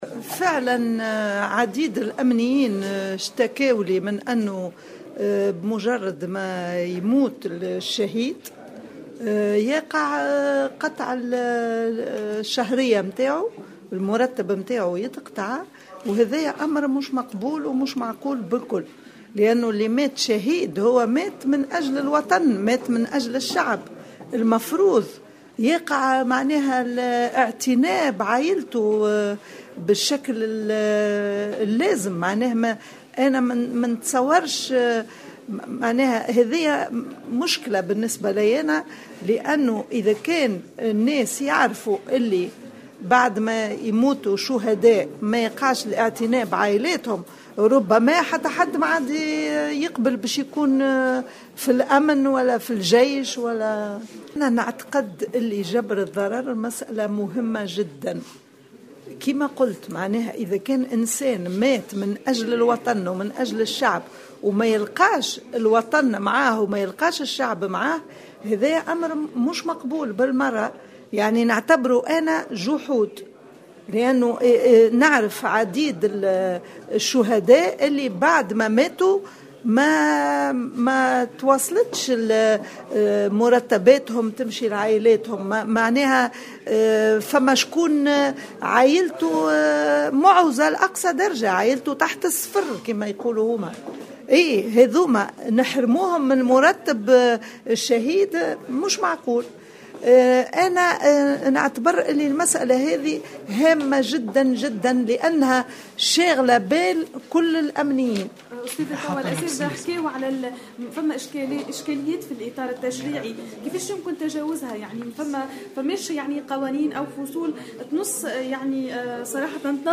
وأضافت خلال ندوة انعقدت اليوم السبت تحت عنوان " حقوق ضحايا الجرائم الإرهابية" أنه يجب الاعتناء بعائلات كل من استشهد في سبيل الوطن والإحاطة بهم.